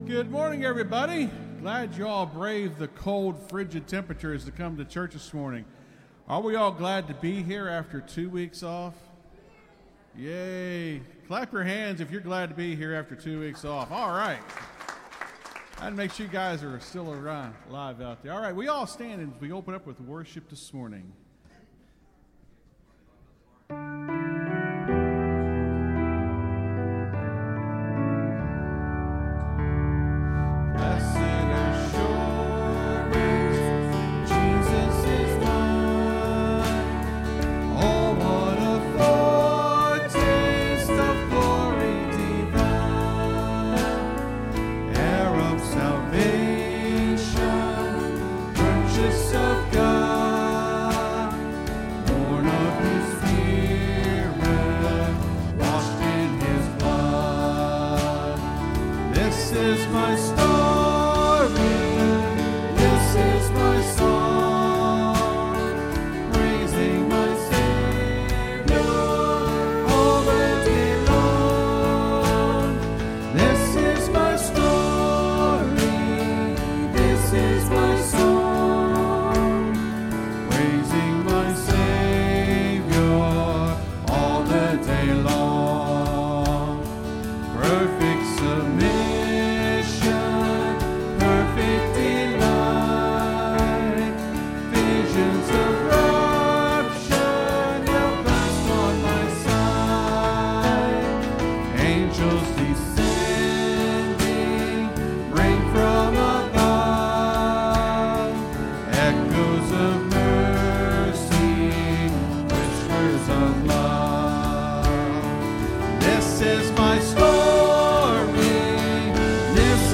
(Sermon starts at 28:25 in the recording).